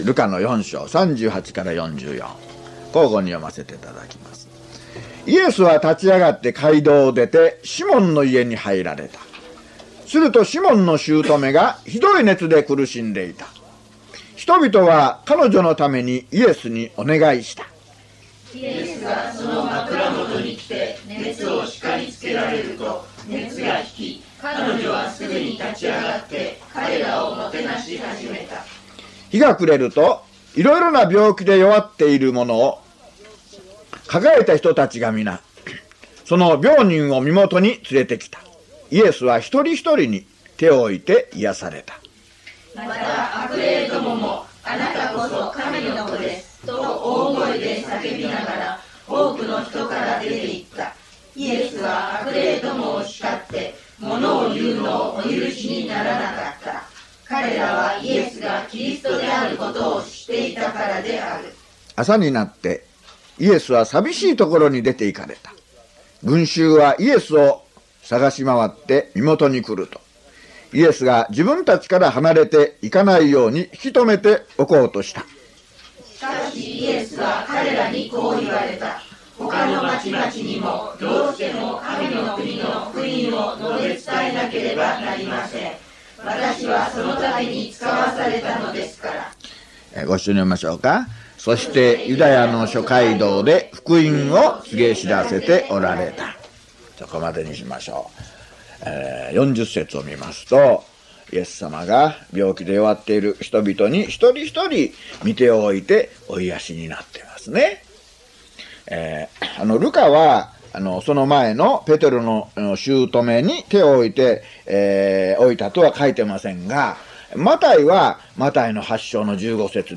luke028mono.mp3